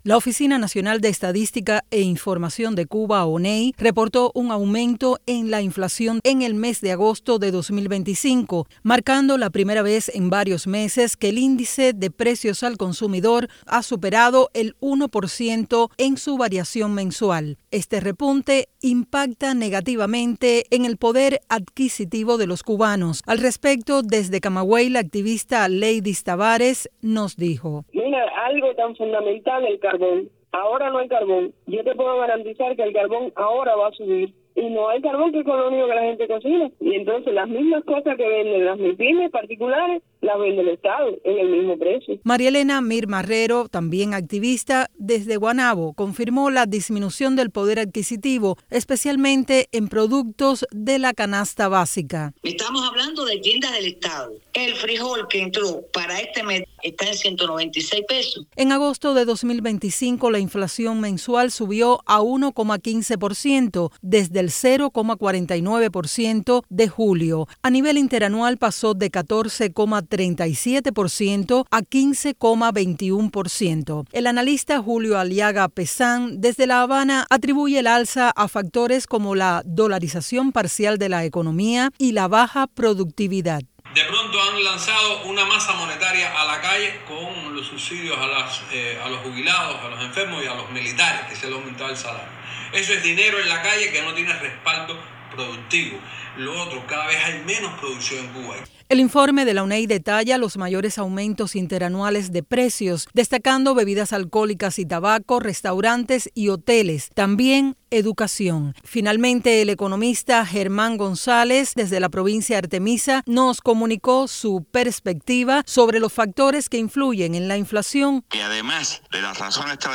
Sube la inflación en Cuba según la ONEI. Cubanos y analistas hablan sobre factores y efectos.
Noticias de Radio Martí